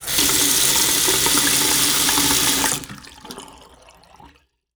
Sink 02
Sink 02.wav